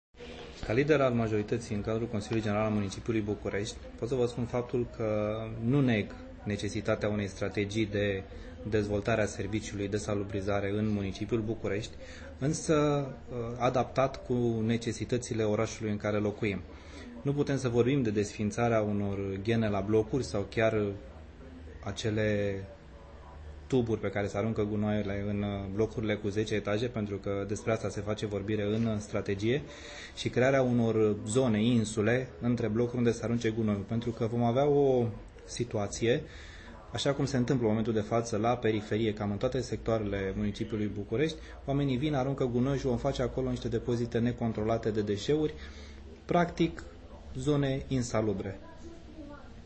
Bogdan Georgescu, șeful grupului majoritar din Consiliul General al Capitalei a declarat, pentru București FM, că nu neagă necesitatea adoptării unei strategii de salubrizare în București, însă aceasta trebuie să fie adaptată la necesitățile orașului.